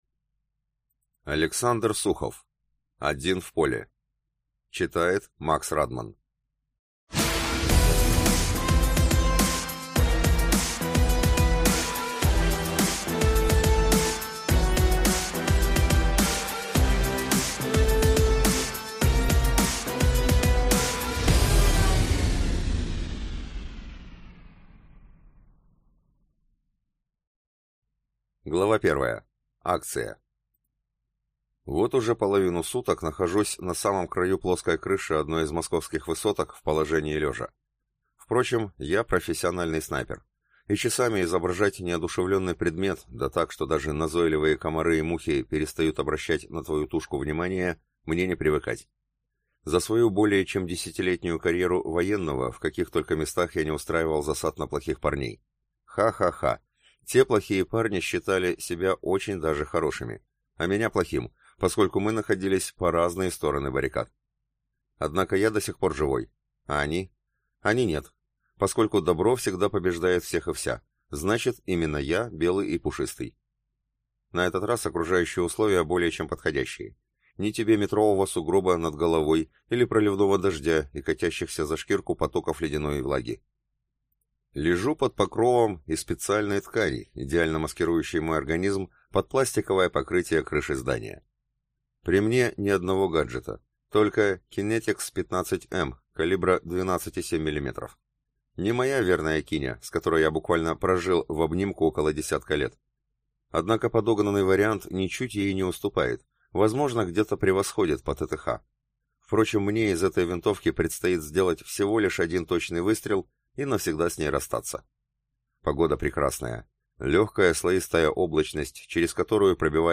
Aудиокнига Лёд.